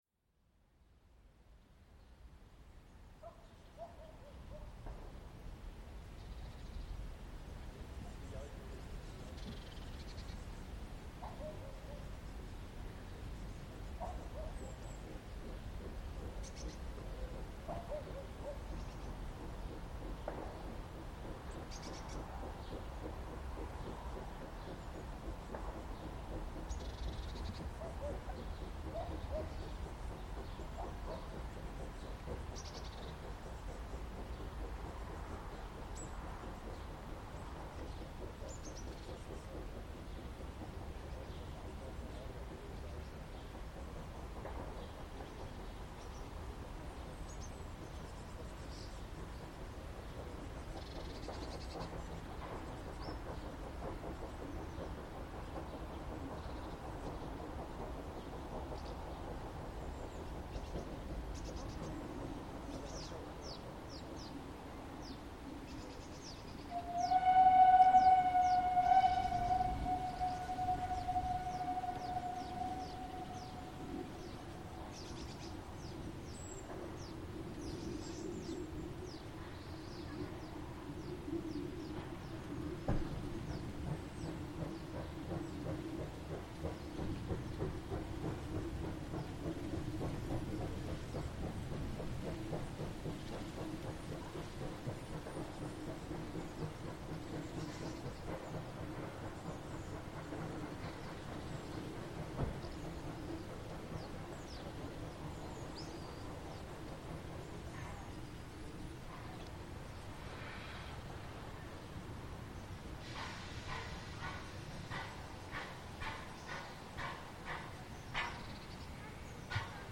98 886 Tv mit Zug von Fladungen nach Mellrichstadt, Ankunft und Ausfahrt Stockheim, um 10:36h am 03.08.2025.   Hier anhören: